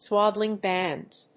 アクセント・音節swád・dling clòthes [bànds]